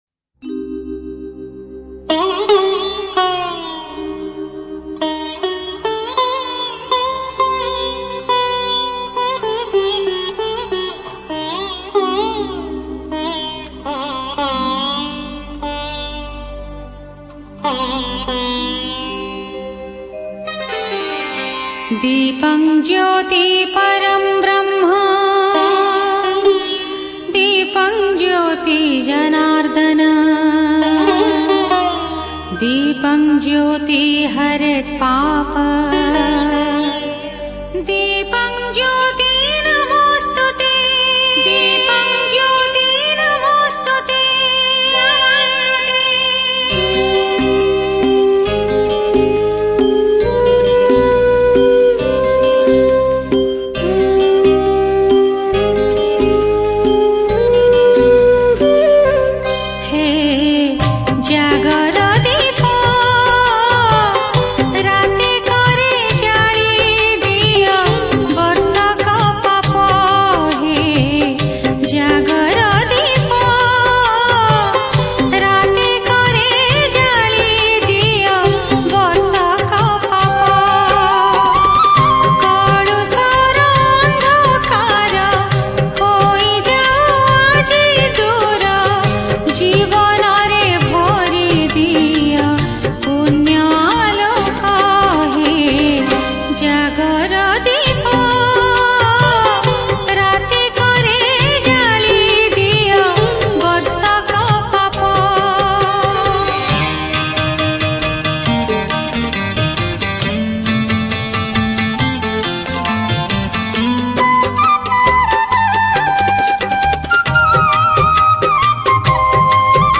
Jagara Special Odia Bhajan Song Upto 2021 Songs Download